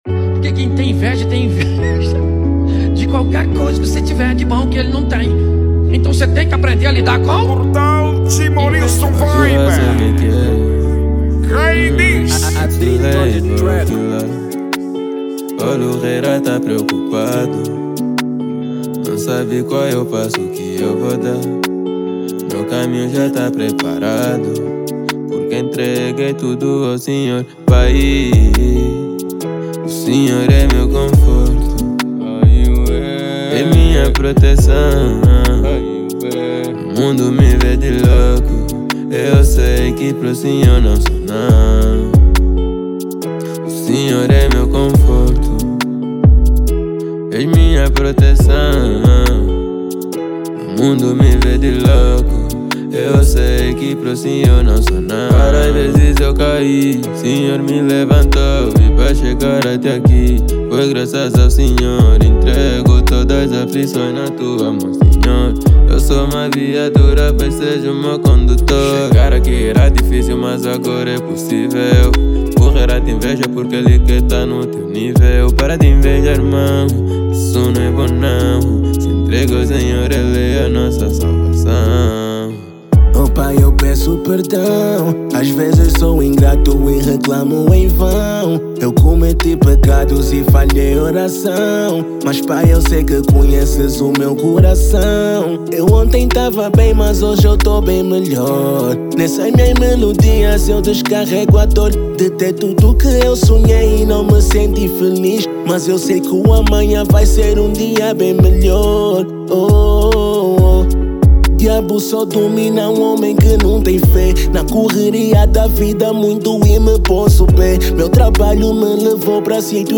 | Rap